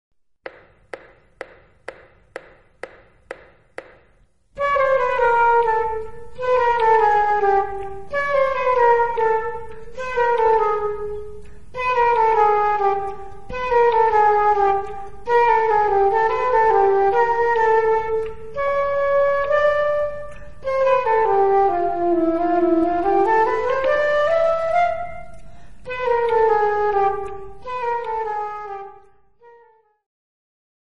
Instrumental Ensembles Flute
A challenge in close harmony playing.
Flute Duet